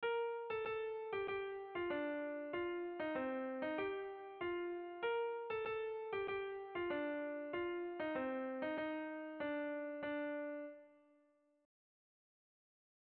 Bertso melodies - View details   To know more about this section
Urtebarri egunez kalez kale kantatzen omen zen Eibarren.